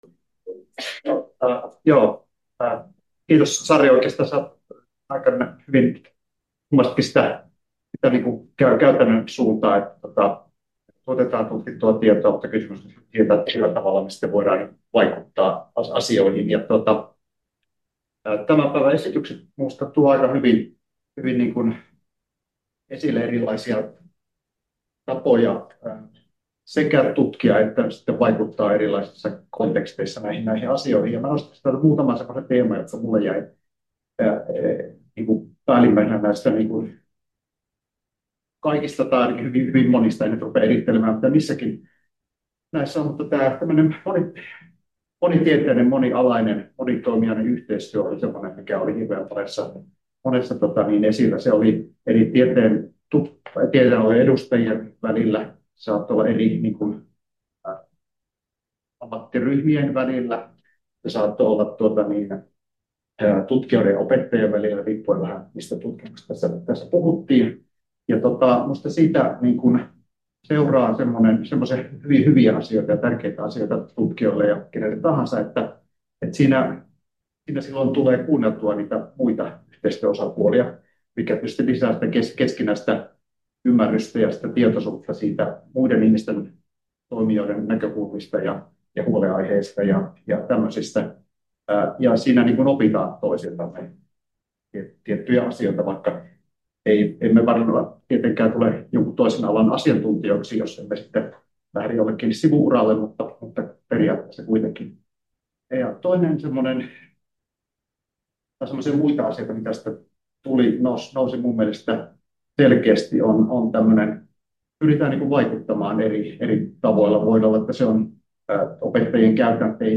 Päätössanat — Moniviestin